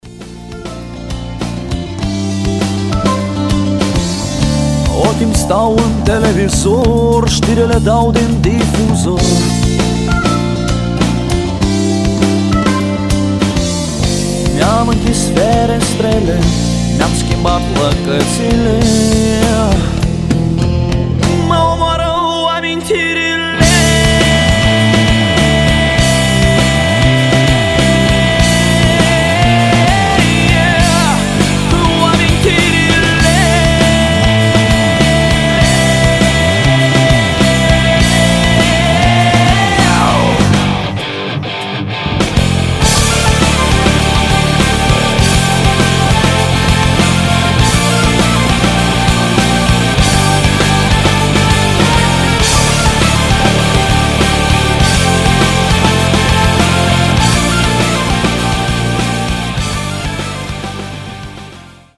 • Качество: 320, Stereo
гитара
мужской вокал
громкие
мелодичные
Alternative Rock